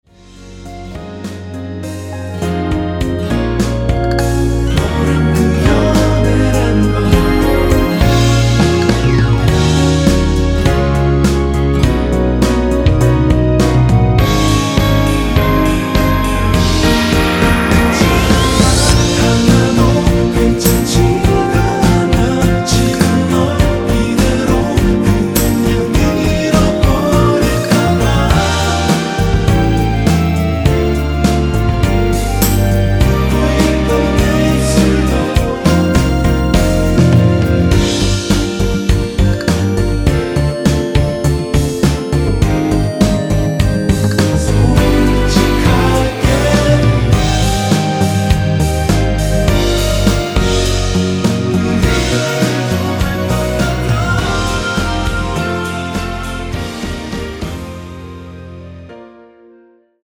원키에서(-1)내린 멜로디와 코러스 포함된 MR입니다.(미리듣기 확인)
Db
앞부분30초, 뒷부분30초씩 편집해서 올려 드리고 있습니다.
중간에 음이 끈어지고 다시 나오는 이유는